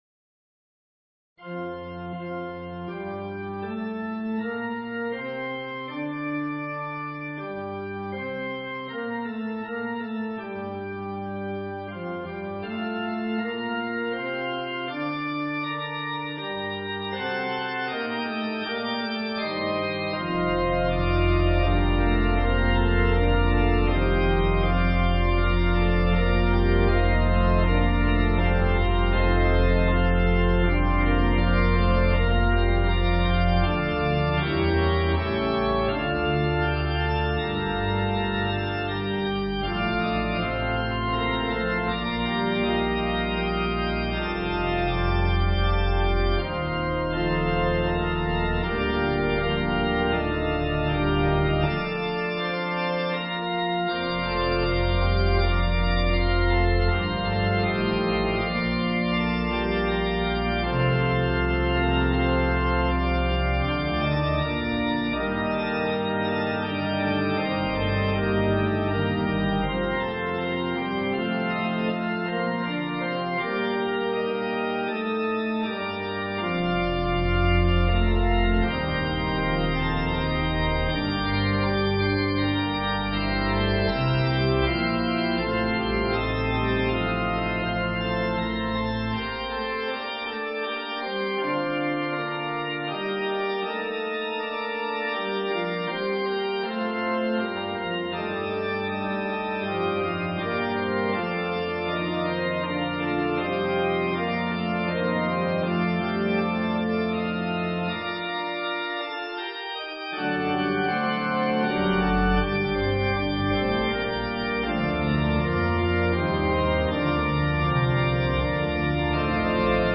A sturdy organ postlude of the restoration.